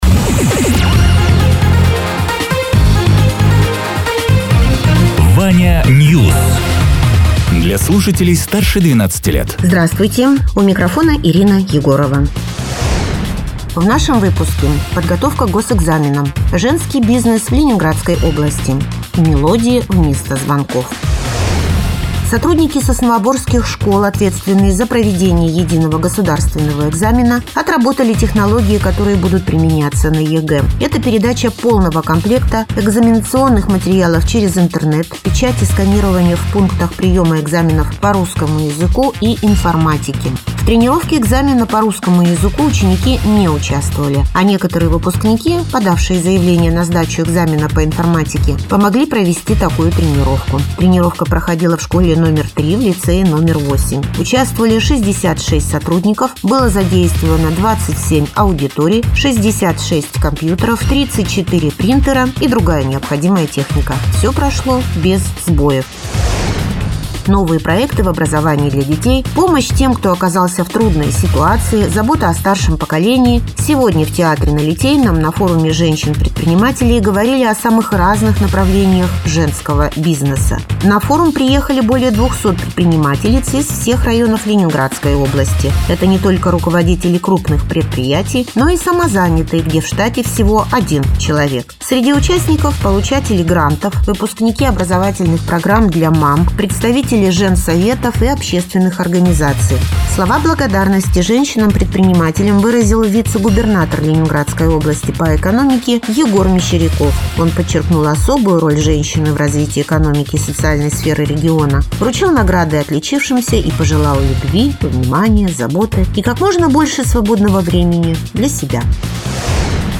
Радио ТЕРА 18.03.2026_10.00_Новости_Соснового_Бора